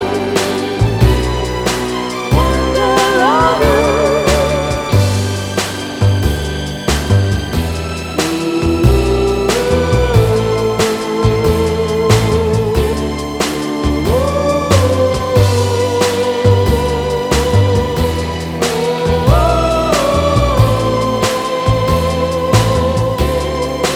Minus Guitars Pop